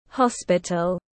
Hospital /ˈhɒs.pɪ.təl/